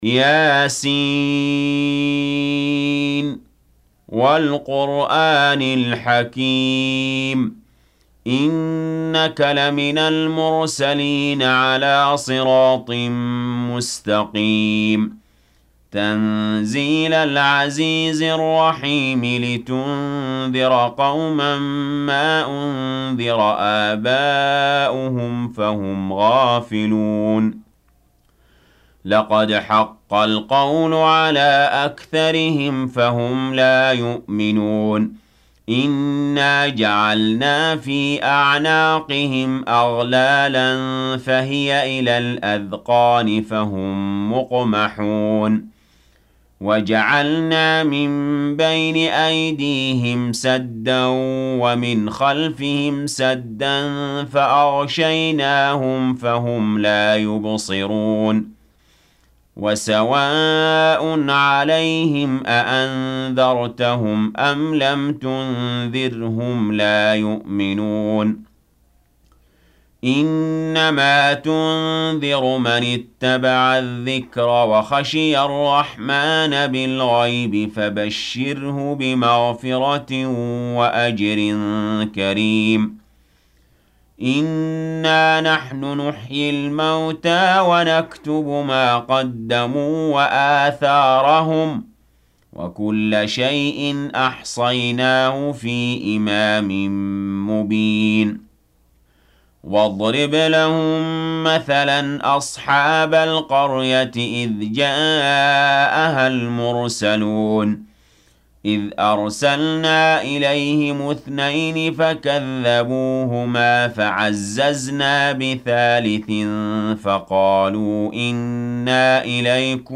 Audio Quran Tarteel Recitation
حفص عن عاصم Hafs for Assem